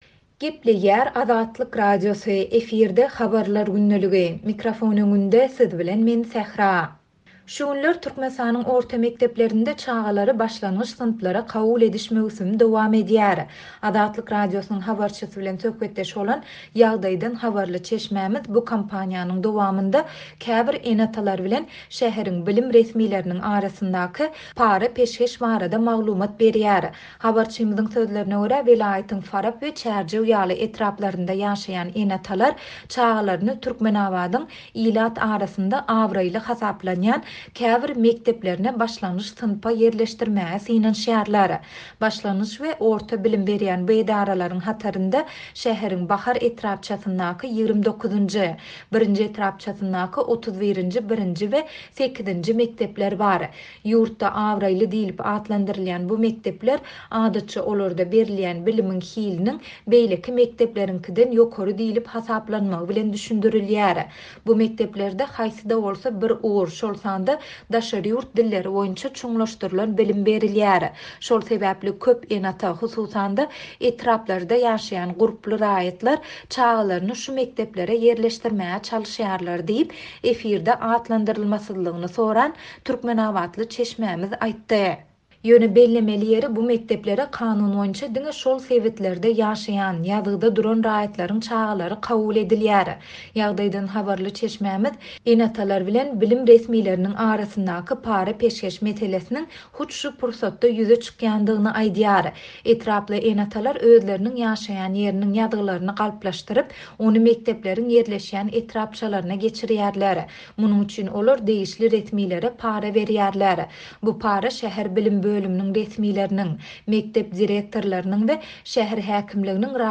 Şu günler Türkmenistanyň orta mekdeplerinde çagalary başlangyç synplara kabul ediş möwsümi dowam edýär. Azatlyk Radiosynyň habarçysy bilen söhbetdeş bolan ýagdaýdan habarly çeşmämiz bu kampaniýanyň dowamynda käbir ene-atalar bilen şäheriň bilim resmileriniň arasyndaky para-peşgeş barada maglumat berýär.